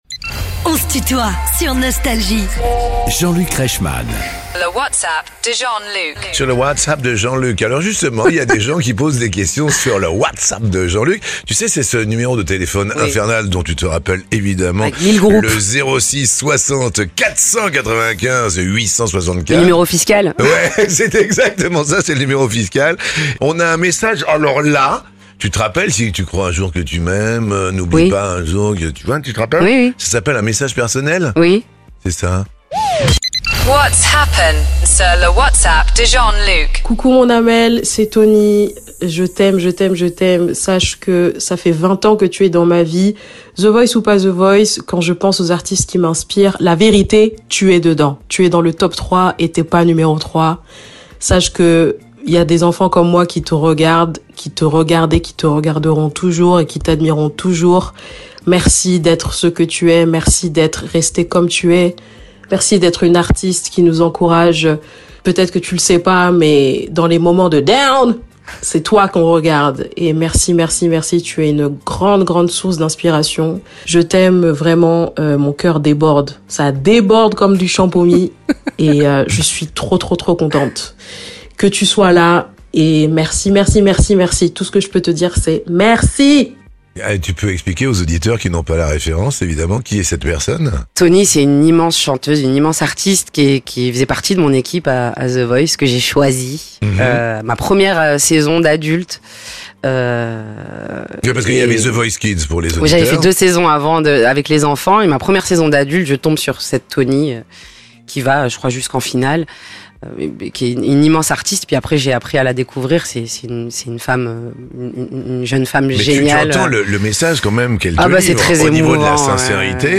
Les interviews
Amel Bent se livre comme jamais dans "On se tutoie ?..." avec